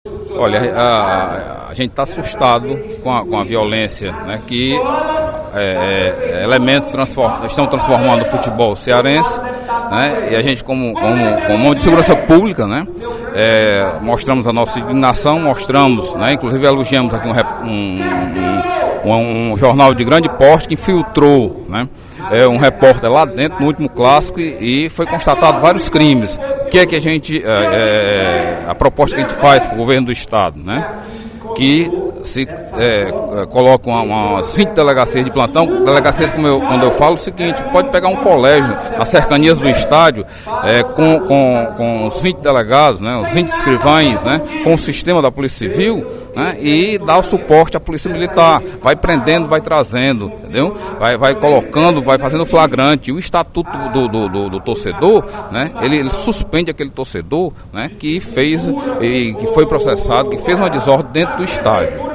O deputado Delegado Cavalcante (PDT) sugeriu, na sessão plenária da Assembleia Legislativa desta terça-feira (08/05), suporte do Governo do Estado às operações policiais para minimizar a violência das torcidas organizadas nos estádios.